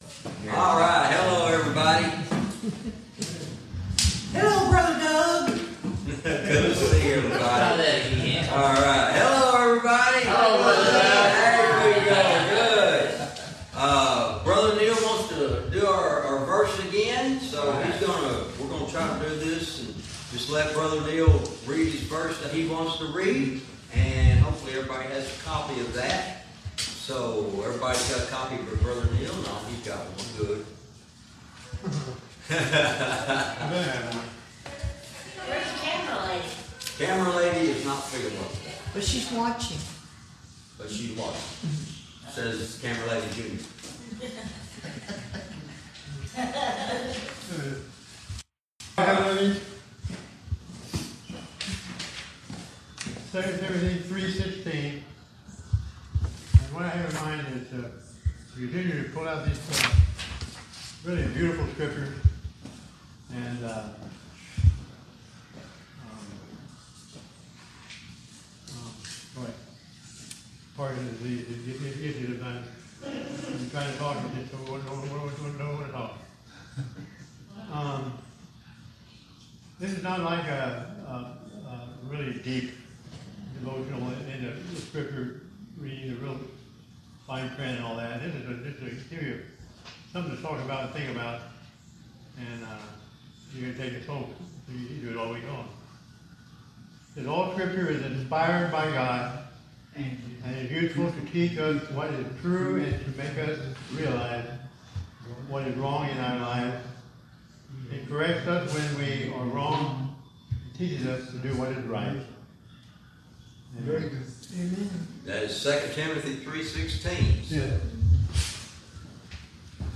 Verse by verse teaching - Jude lesson 105 verse 24